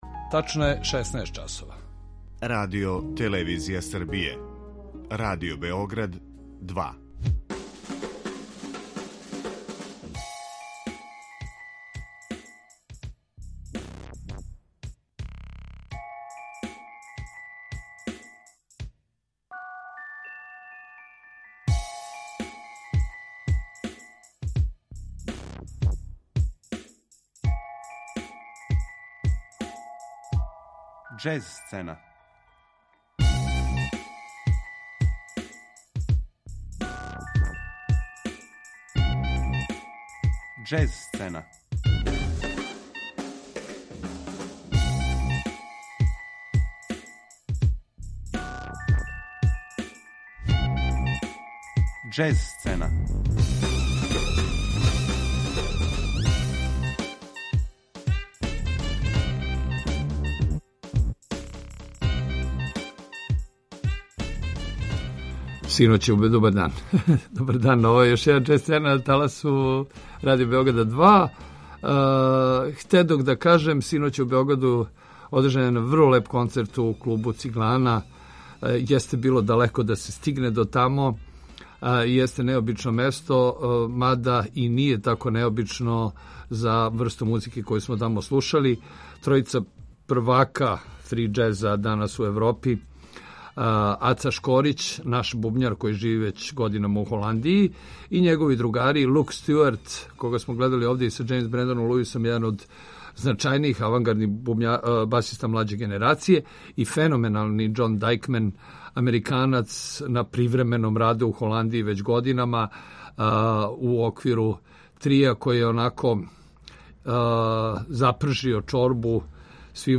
саксофон
бас
бубњеви